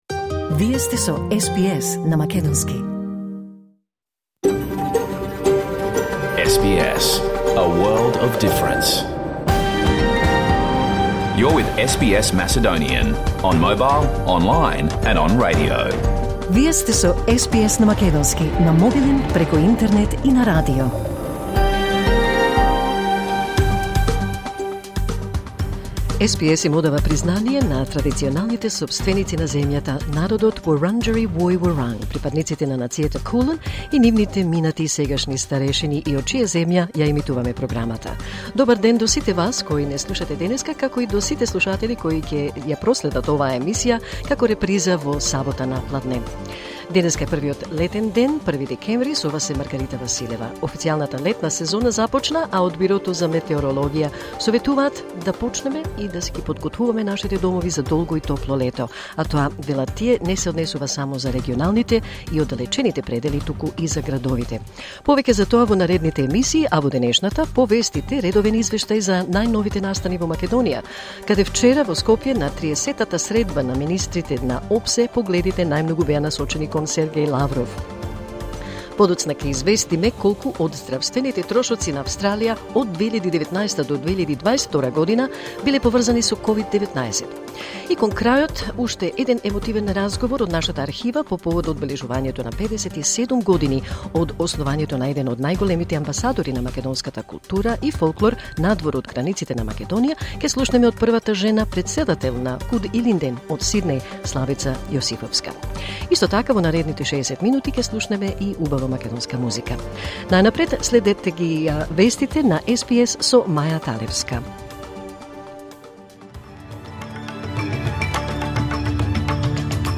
SBS Macedonian Program Live On Air 1st December 2023